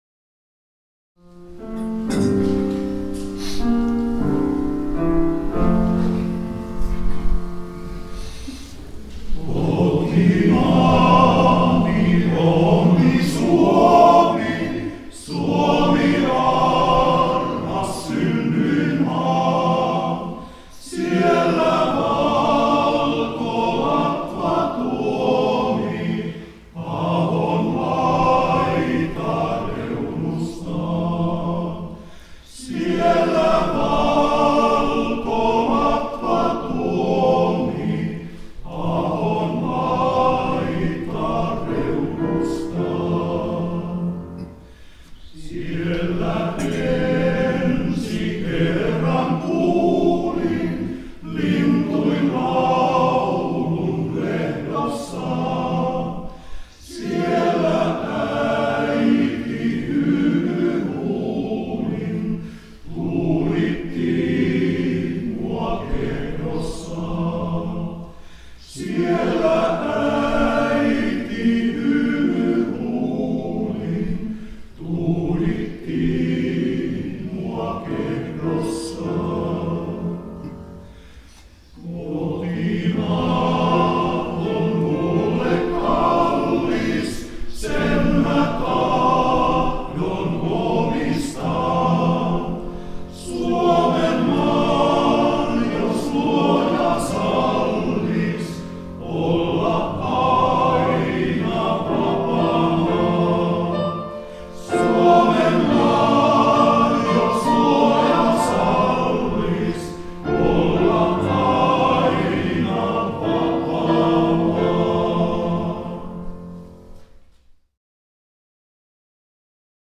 Kiitos Isänmaasta – kuorokonsertti 29.10.2017
Taipalsaaren Lauluveikot ja Taipalsaaren kirkkokuoro pitivät yhteisen konsertin Taipalsaaren kirkossa. Ohjelmistossa isänmaallisia ja hengellisiä lauluja.